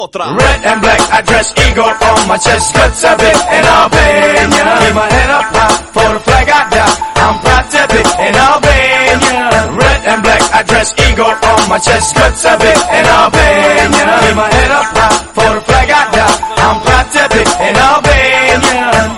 Sound Buttons: Sound Buttons View : Albanian Chorus
albanian-chorus.mp3